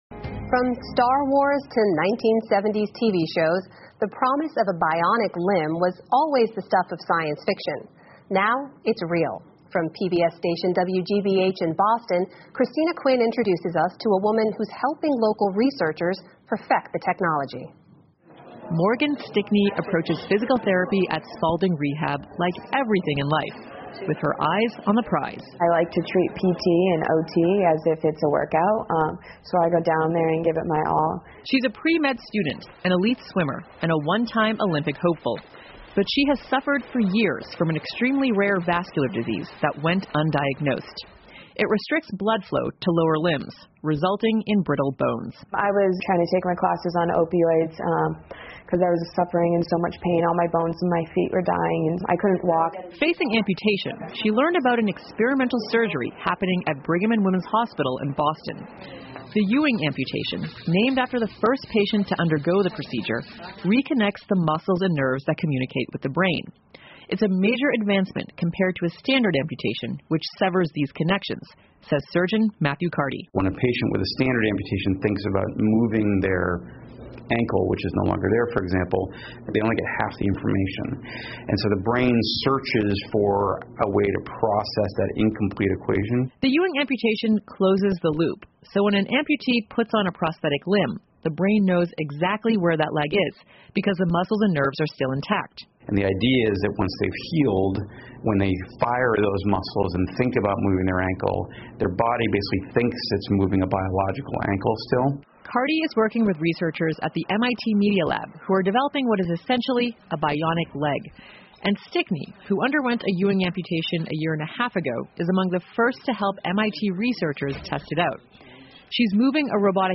PBS高端访谈:仿生肢体帮助实现梦想 听力文件下载—在线英语听力室